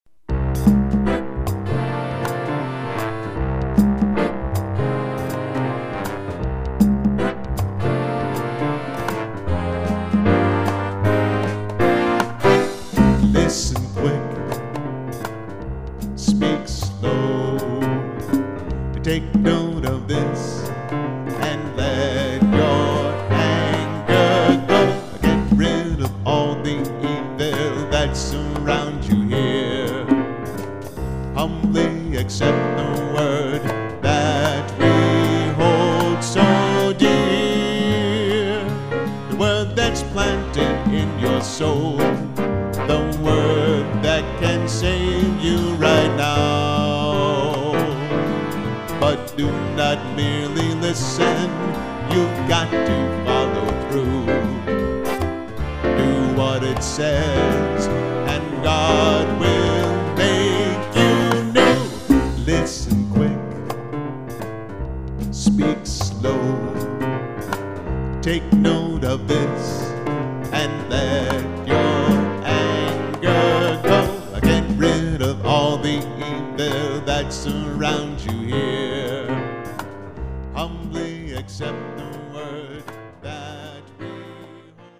Contemporary Christian music
Keyboard/Vocals